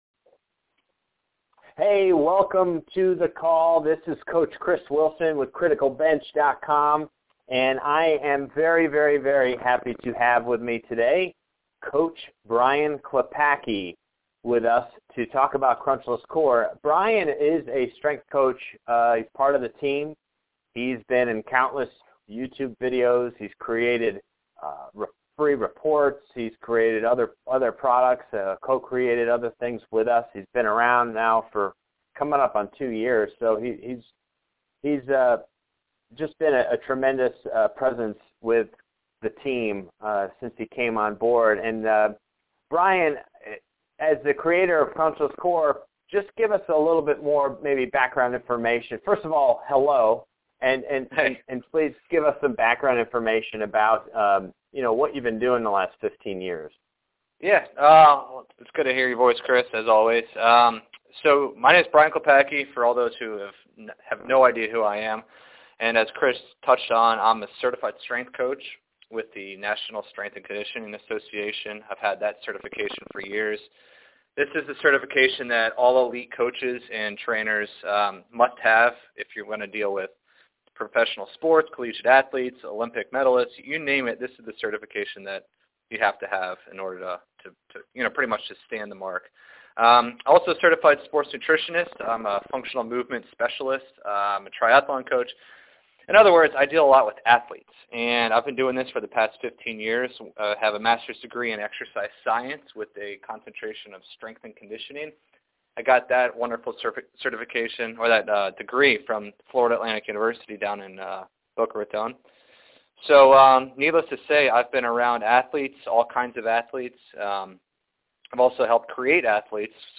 Crunchless Core FREE MP3 Interview <---- Direct Secure Download Link to the MP3 File Keep training hard